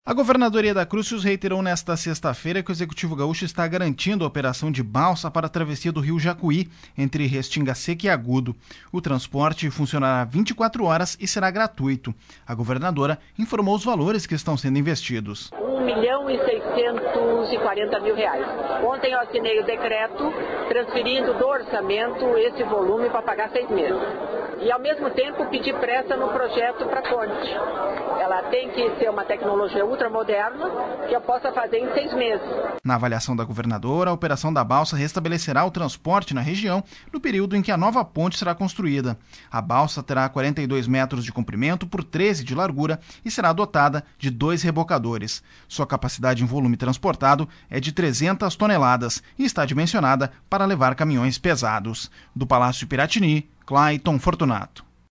Em entrevista nesta sexta-feira (12), na qual explicou o decreto assinado para liberar as operações da balsa e normalizar o tráfego na RSC - 287, entre Restinga Seca e Agudo, através de operações hidroviárias, a governadora Yeda Crusius observou que os